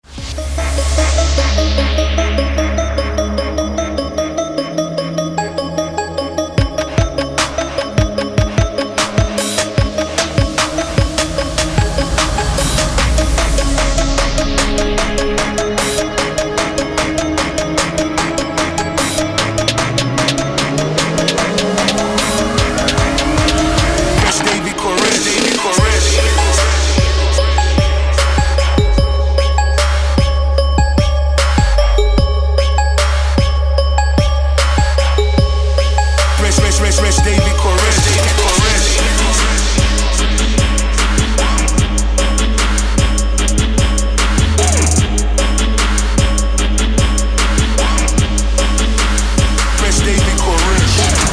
• Качество: 256, Stereo
мужской вокал
Electronic
club
electro
страшные
Жанр: Electronic